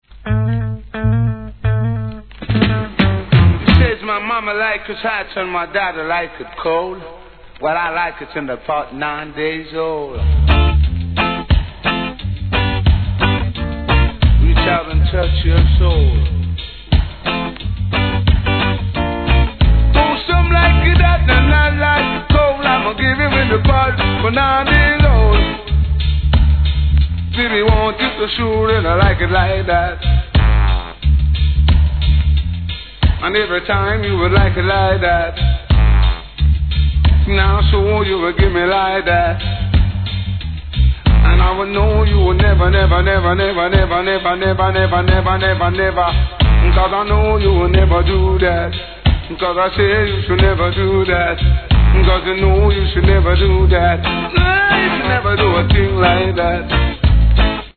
REGGAE
さすがのDeeJay CUT!!!